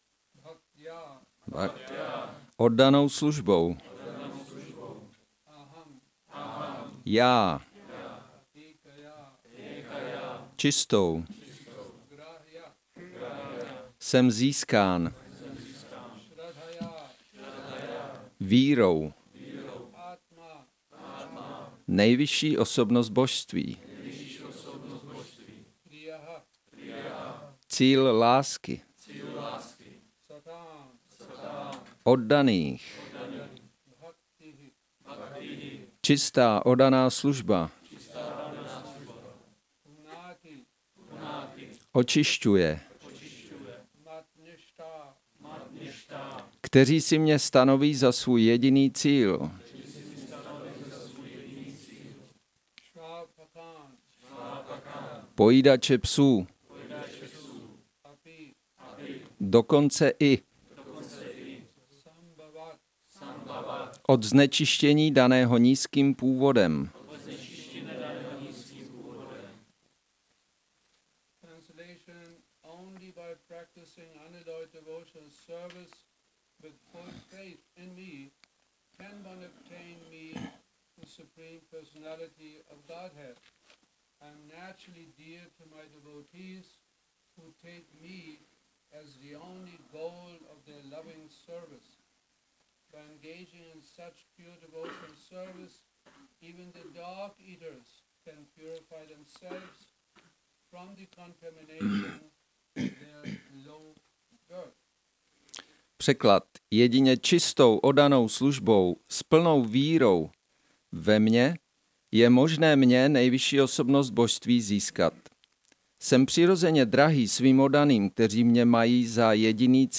Přednáška SB-11.14.21 – Šrí Šrí Nitái Navadvípačandra mandir